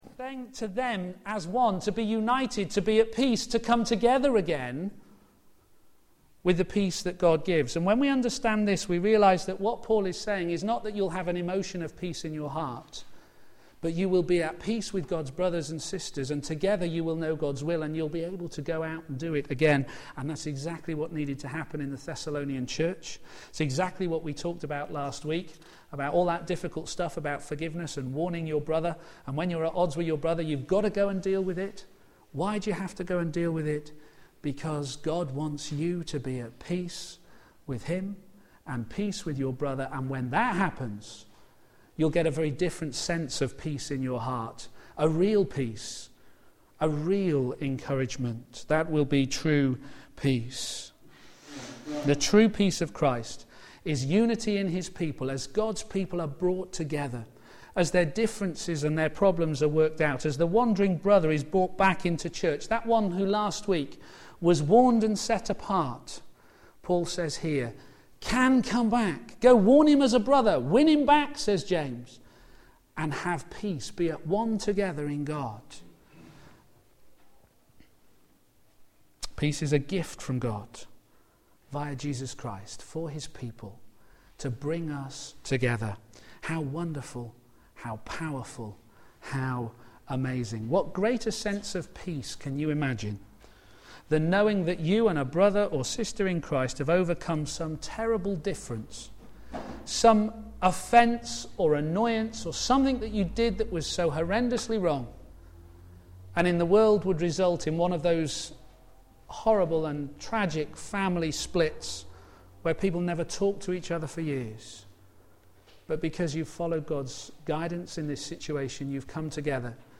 Media for a.m. Service on Sun 03rd Jul 2011 10:30
Series: A Church to be Proud of and Thankful for Theme: The Peaceful Life Sermon